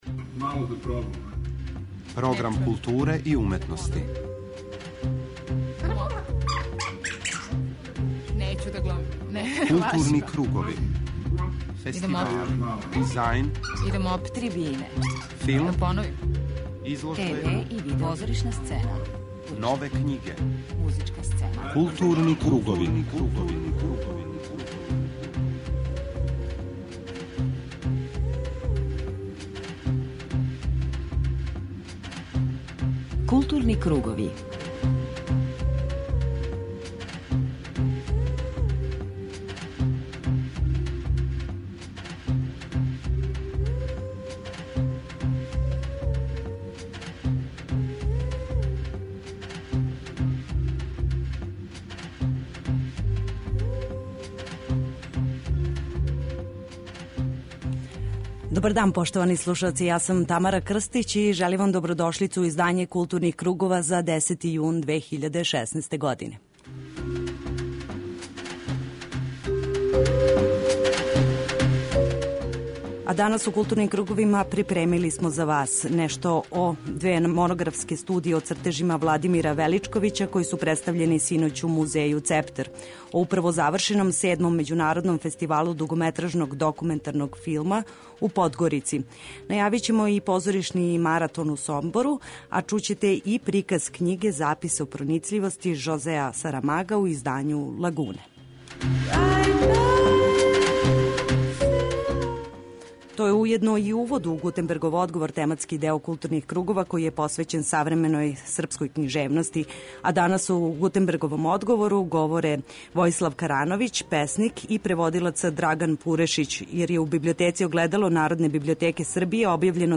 преузми : 41.04 MB Културни кругови Autor: Група аутора Централна културно-уметничка емисија Радио Београда 2.